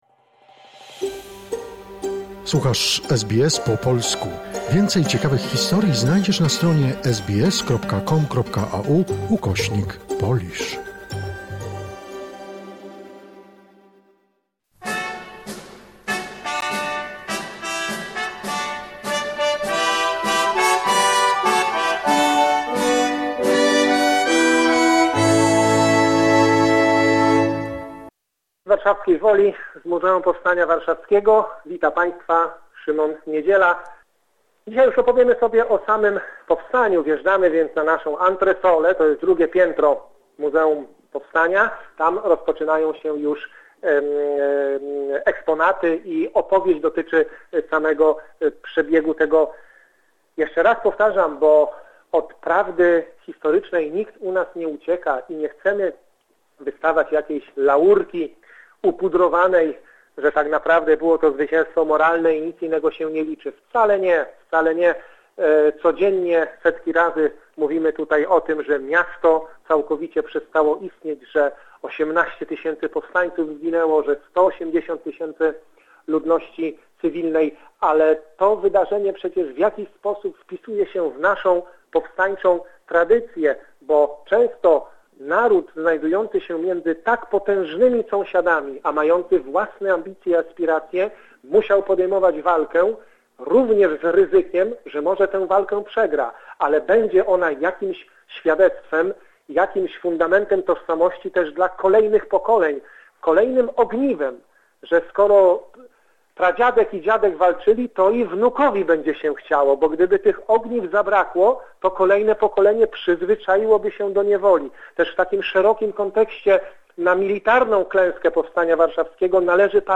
Muzeum Powstania Warszawskiego jest symbolem nierównej, ale heroicznej walki Polaków z niemieckim okupantem podczas II Wojny Światowej. Po najbardziej popularnym muzeum w Polsce oprowadza nas historyk